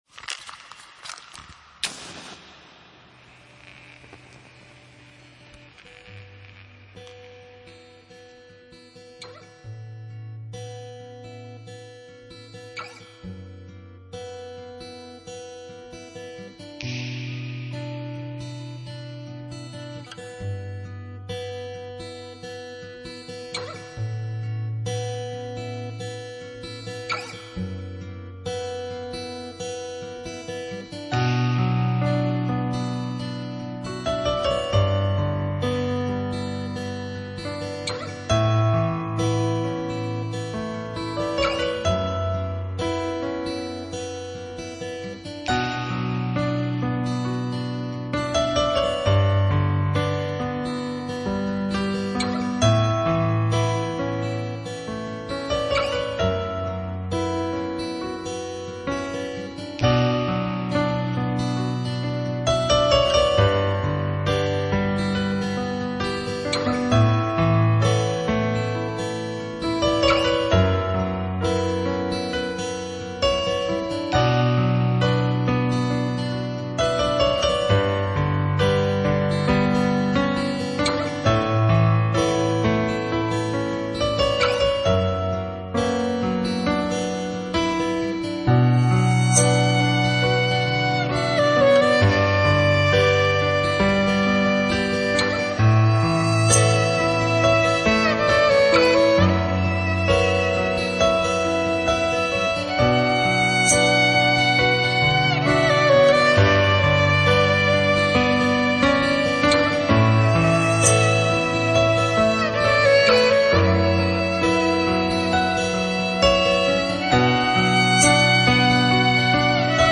per_melodija_gitaraskripka________im.mp3